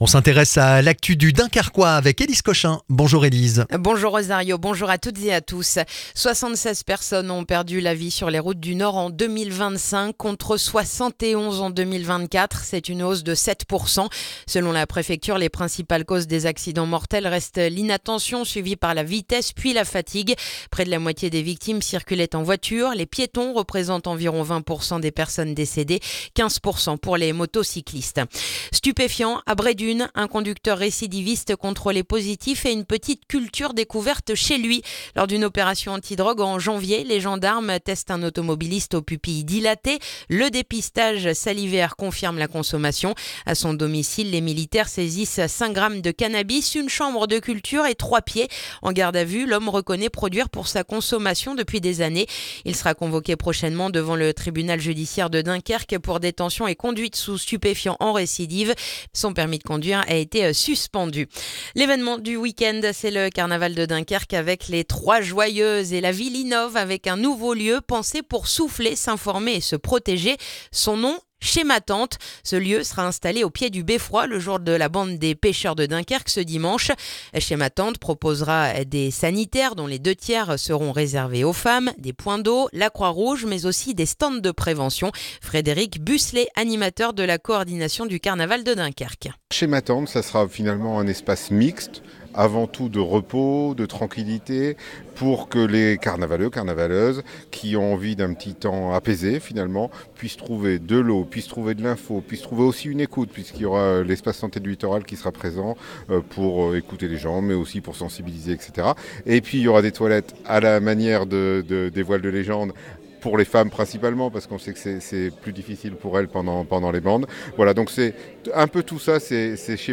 Le journal du vendredi 13 février dans le dunkerquois